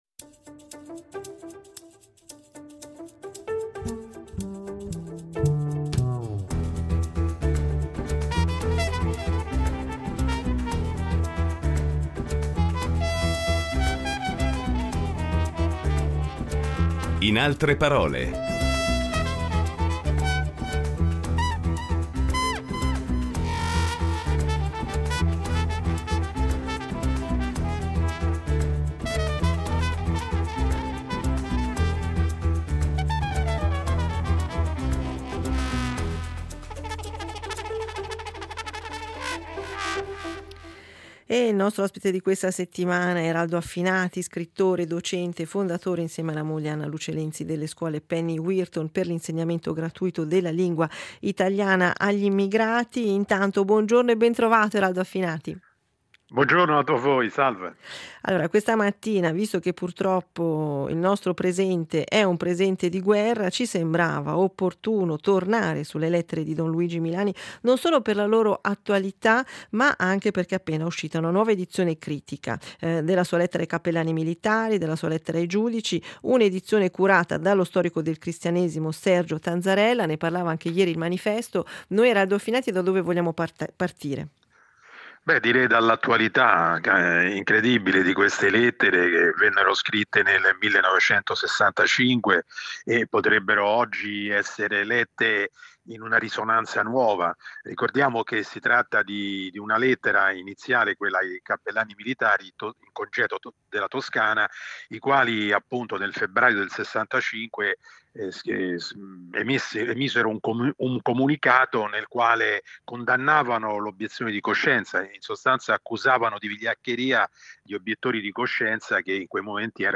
Commento d’attualità con lo scrittore e insegnante